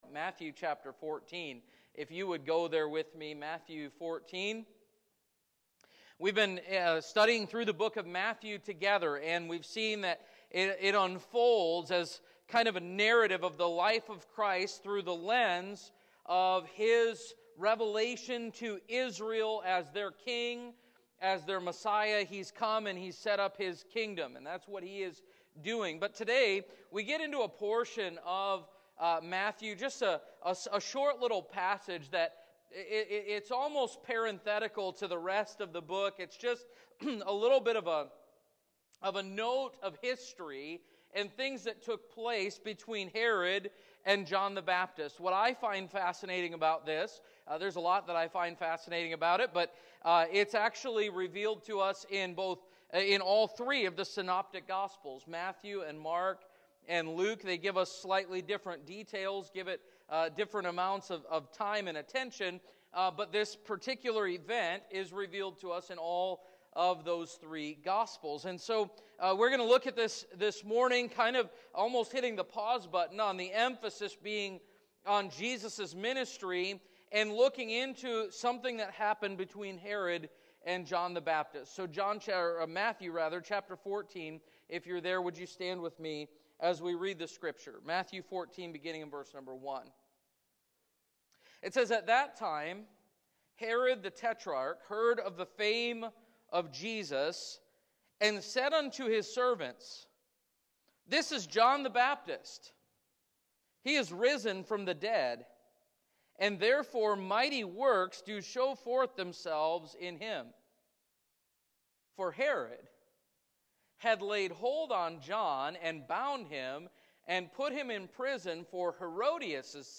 1 Menopause Masterclass: Your Complete Guide with Dr. Mary Claire Haver 50:49 Play Pause 20m ago 50:49 Play Pause Play later Play later Lists Like Liked 50:49 Dr. Mary Claire Haver breaks down everything you need to know about perimenopause, menopause, and postmenopause in this solo episode, answering critical questions about hormones, symptoms, and treatment with the medical expertise and personal experience that's made her a trusted voice for millions of women.